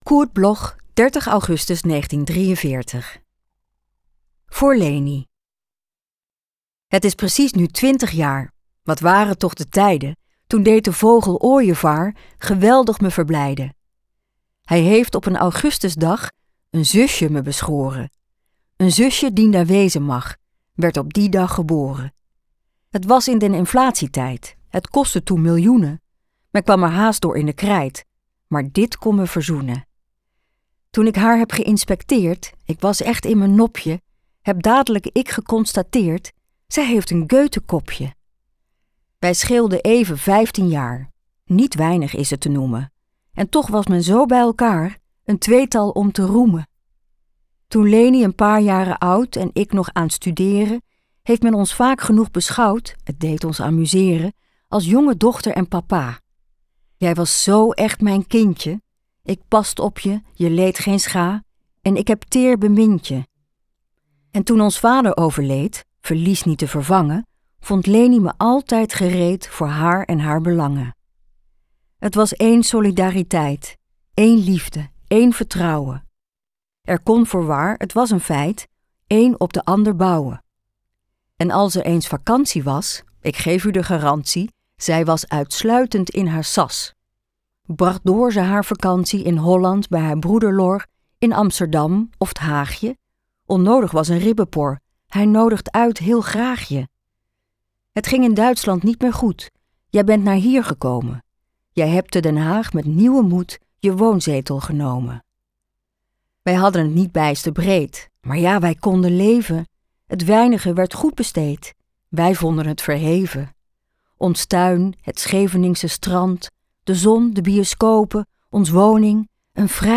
Aufnahme: Teejay Studio, Amsterdam · Bearbeitung: Kristen & Schmidt, Wiesbaden